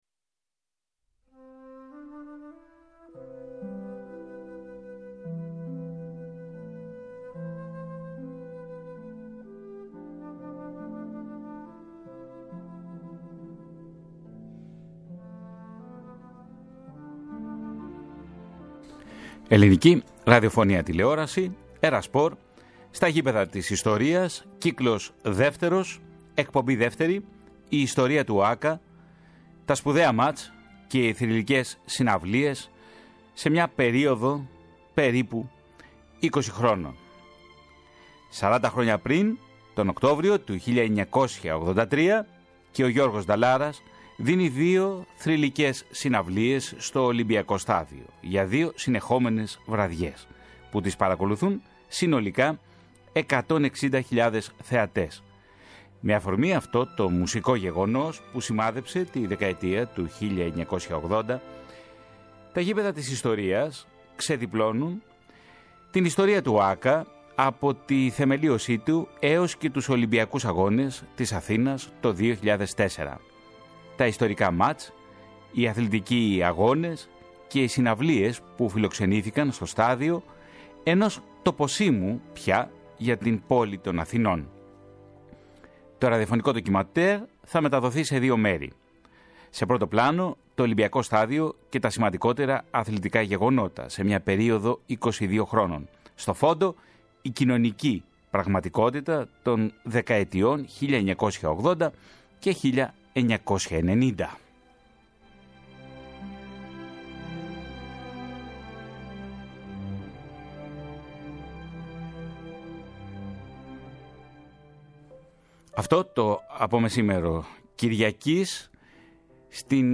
To ραδιοφωνικό ντοκιμαντέρ θα μεταδοθεί σε δύο μέρη, σε πρώτο πλάνο το Ολυμπιακό Στάδιο και τα σημαντικότερα αθλητικά γεγονότα σε μία περίοδο 22 χρόνων, στο φόντο η κοινωνική πραγματικότητα των δεκαετιών 1980 και 1990. Στο πρώτο μέρος παρουσιάζονται η θεμελίωση του ΟΑΚΑ από τον τότε Πρόεδρο της Δημοκρατίας Κωνσταντίνο Καραμανλή αλλά και η διεξαγωγή του Πανευρωπαϊκού Πρωταθλήματος Στίβου το 1982, ενώ ακούγονται και χαρακτηριστικά αποσπάσματα από τις συναυλίες του Γιώργου Νταλάρα.